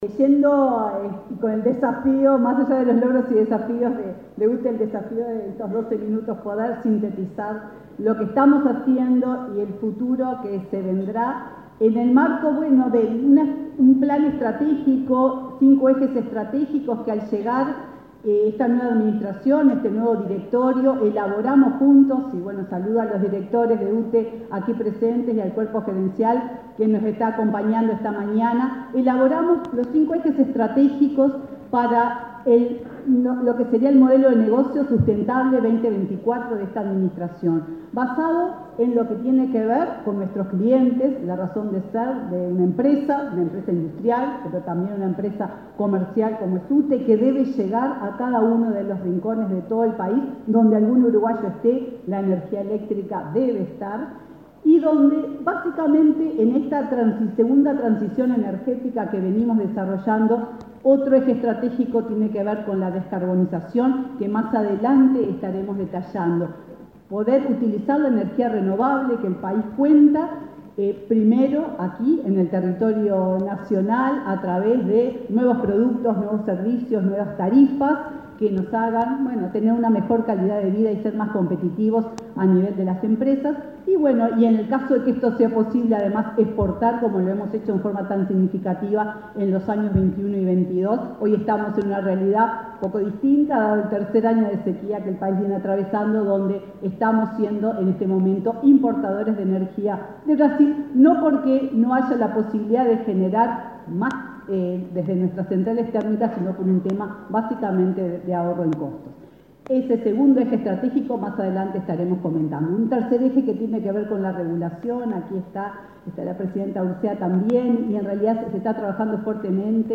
Disertación de la presidenta de UTE, Silvia Emaldi
Disertación de la presidenta de UTE, Silvia Emaldi 04/07/2023 Compartir Facebook X Copiar enlace WhatsApp LinkedIn La presidenta de la UTE, Silvia Emaldi, disertó este martes 4 en Montevideo, en un desayuno de trabajo organizado por la Asociación de Dirigentes de Marketing.